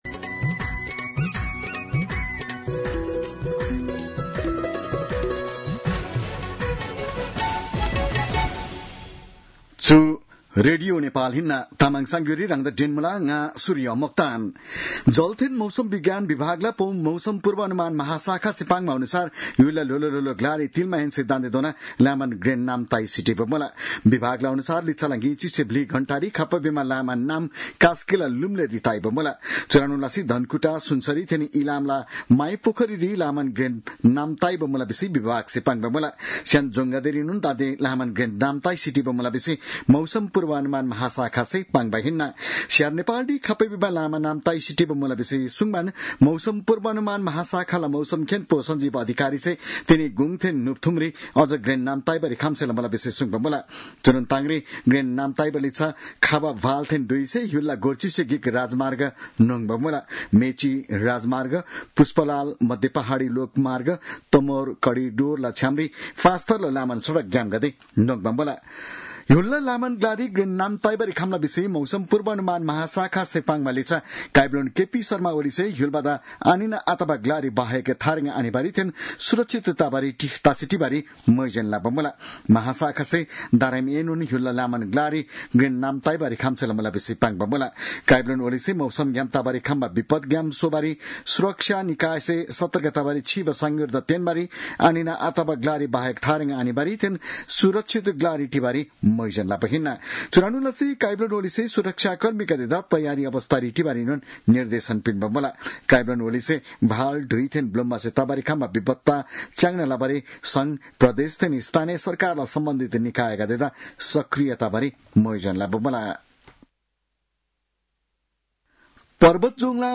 तामाङ भाषाको समाचार : ४ साउन , २०८२